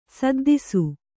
♪ saddisu